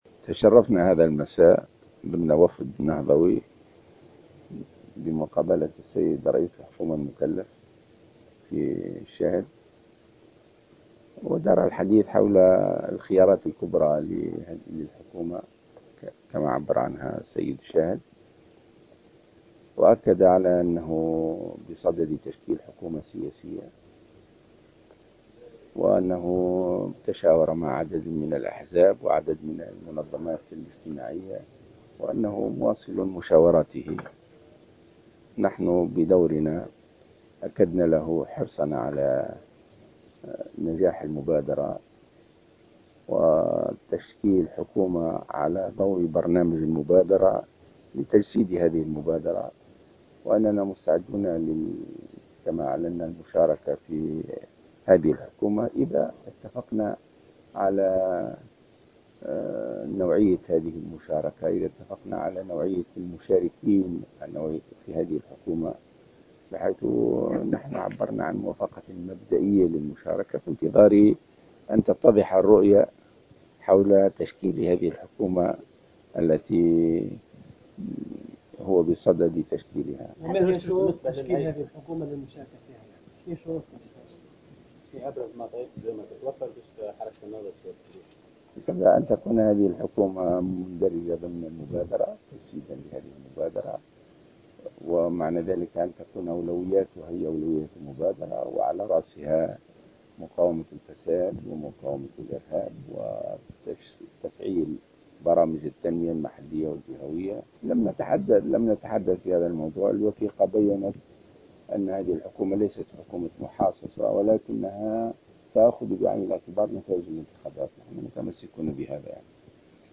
قال رئيس حركة النهضة راشد الغنوشي، في تصريح لمراسلة الجوهرة أف أم اليوم الخميس،...